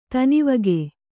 ♪ tanivage